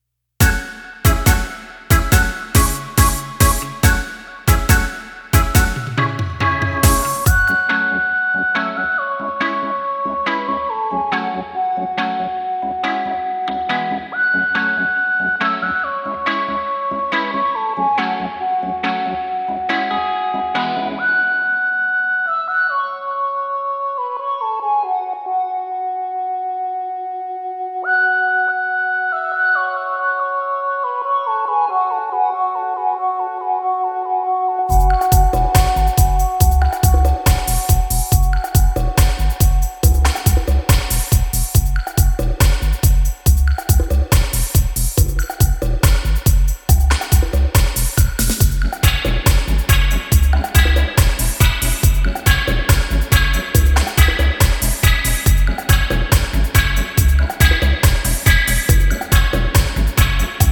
killer steppers relick